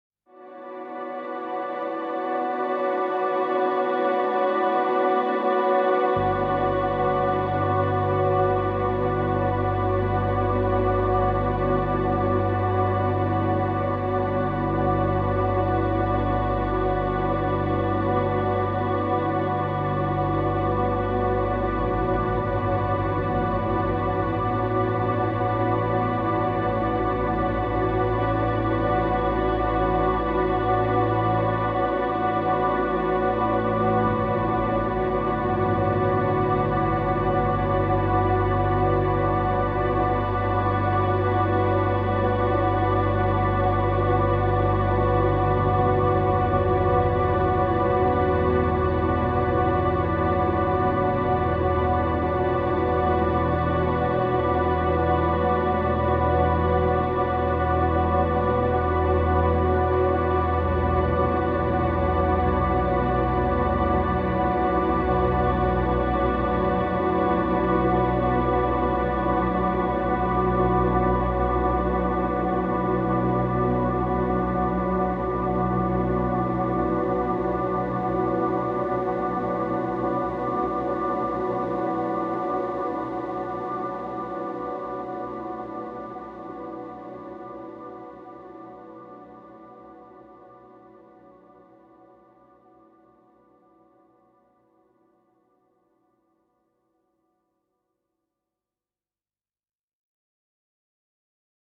Bass Drone Version